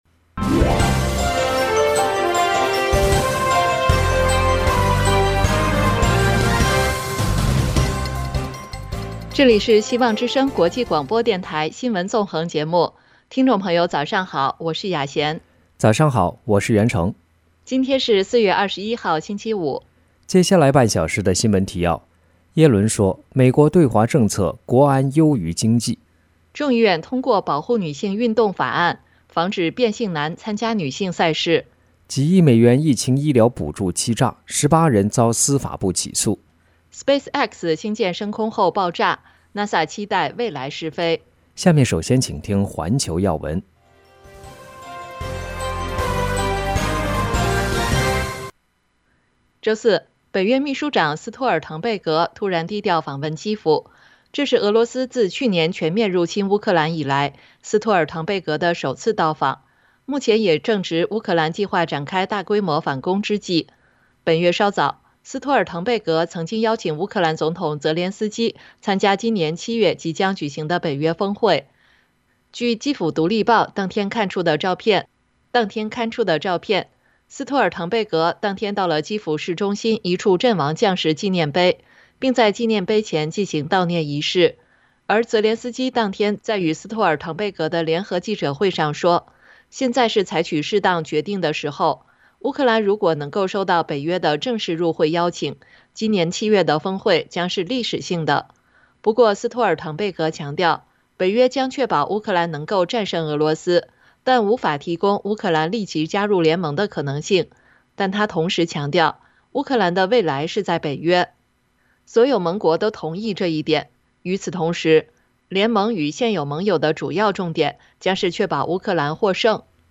新聞提要（上半場） 環球新聞 1、北约秘书长低调访问基辅 为俄罗斯入侵以来首次